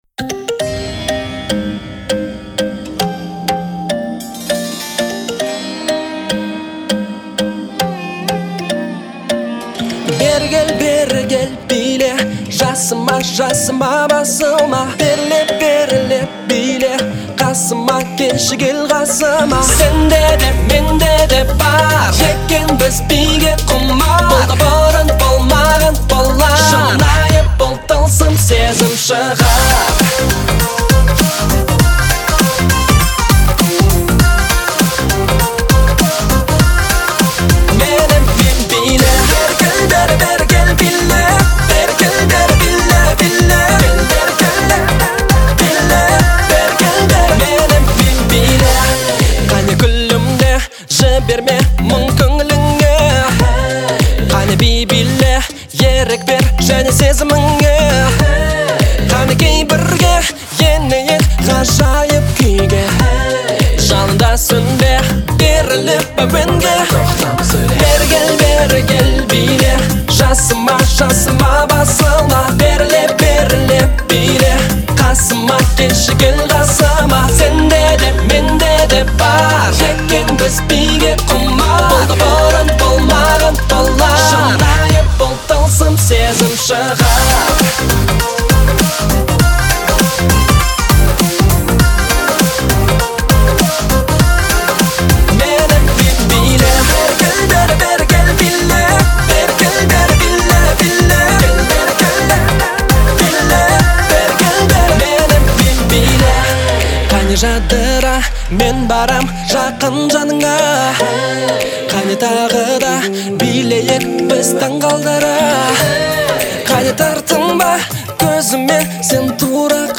отличается мелодичностью и запоминающимся припевом